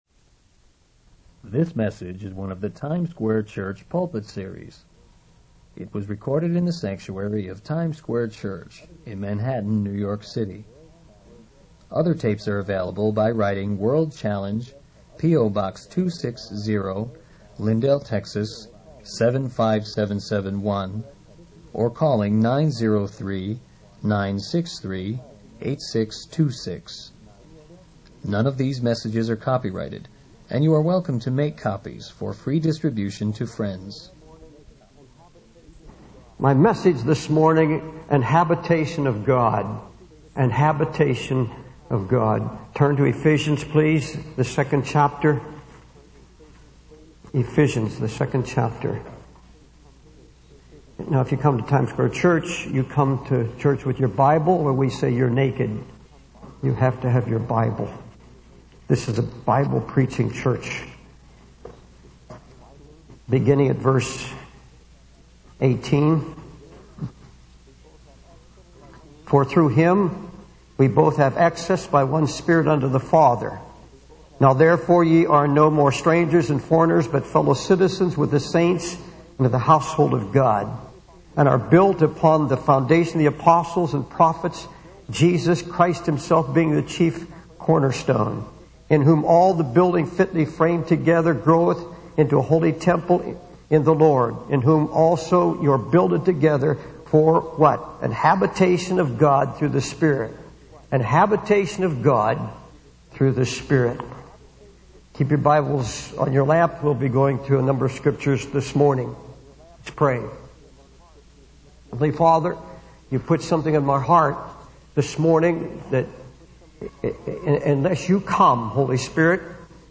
In this sermon, the preacher emphasizes Jesus' anticipation and delight in the future.
It was recorded in the sanctuary of Times Square Church in Manhattan, New York City.